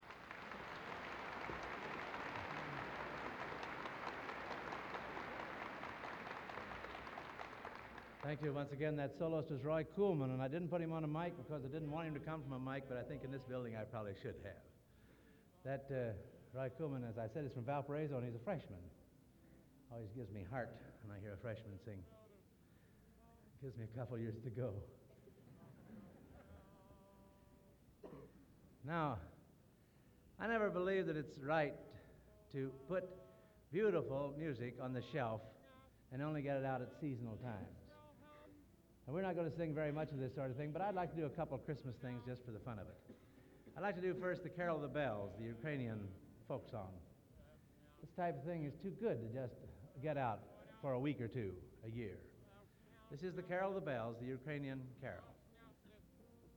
Collection: Hammond Concert, 1960
Genre: | Type: Director intros, emceeing